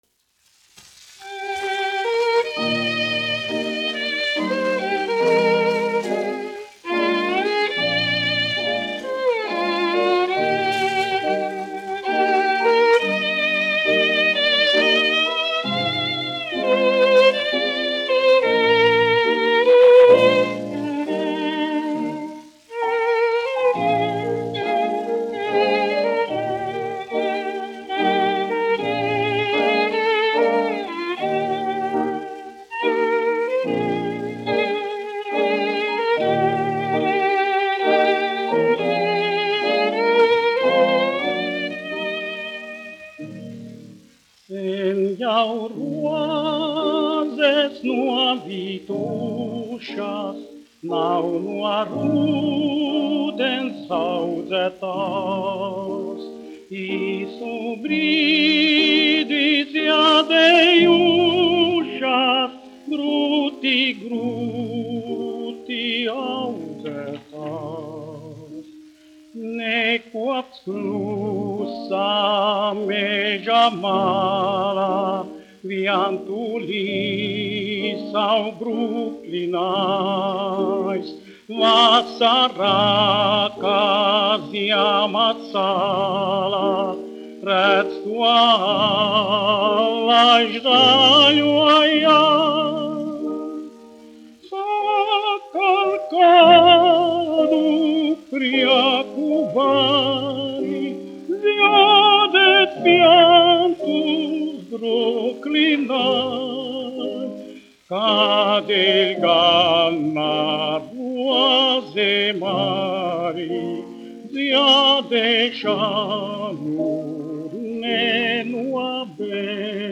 1 skpl. : analogs, 78 apgr/min, mono ; 25 cm
Populārā mūzika -- Latvija
Skaņuplate
Latvijas vēsturiskie šellaka skaņuplašu ieraksti (Kolekcija)